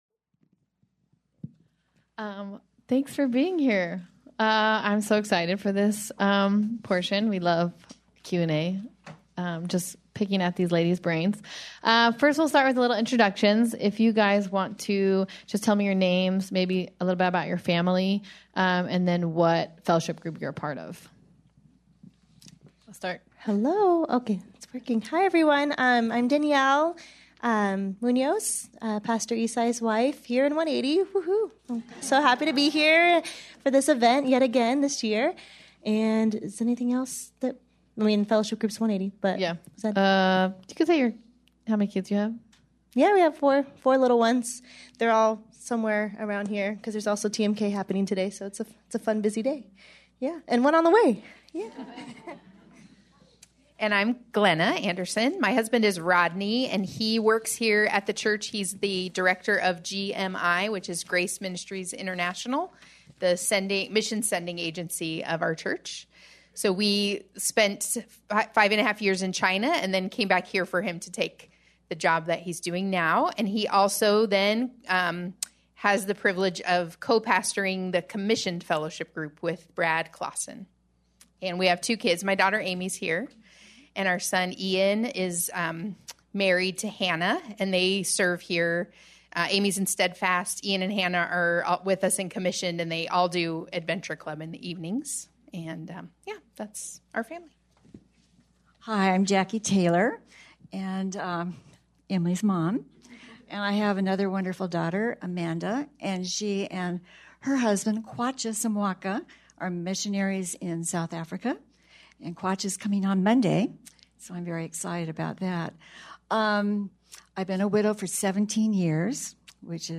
Q&A Panel, Part 1